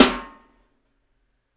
SNARE 3.wav